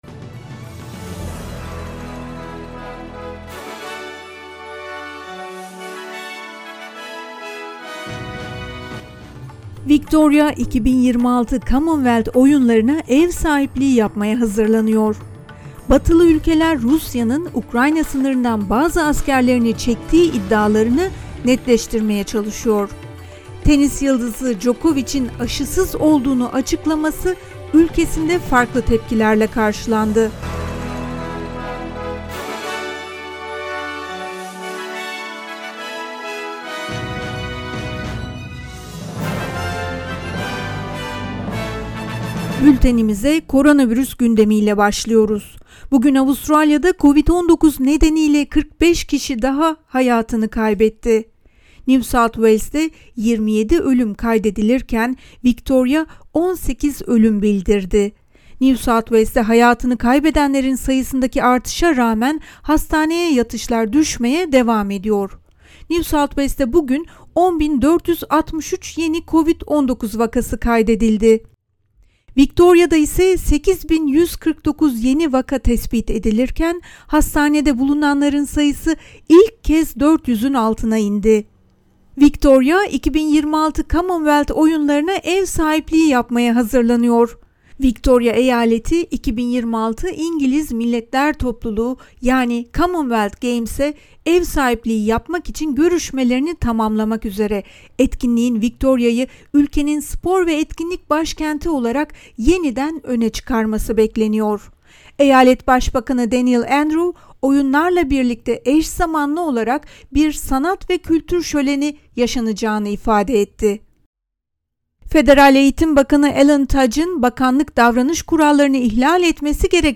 SBS Türkçe Haber Bülteni 16 Şubat